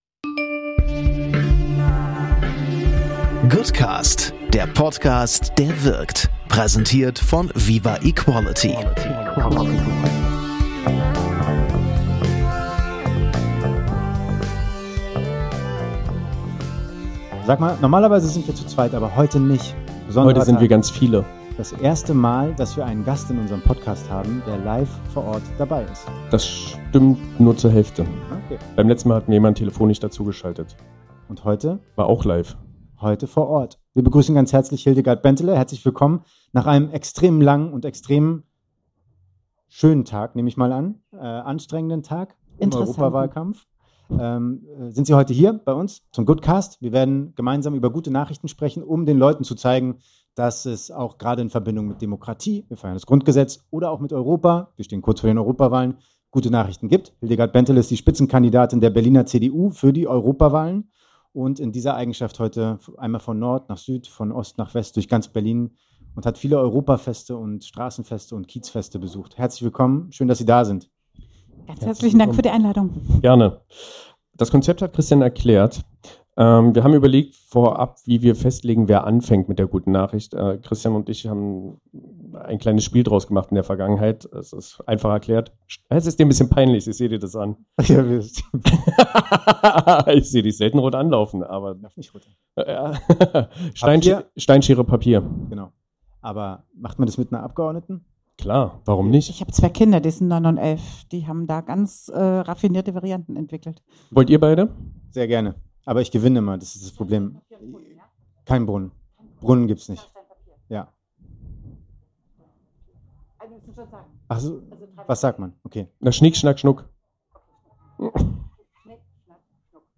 #54 Spezial - Live vom Demokratiefest zu 75 Jahren Grundgesetz mit Hildegard Bentele ~ Goodcast Podcast
Wenn du glaubst, verrückter geht es nicht, dann solltest du diese Folge nicht verpassen, live vom Demokratiefest zu 75 Jahren Grundgesetz.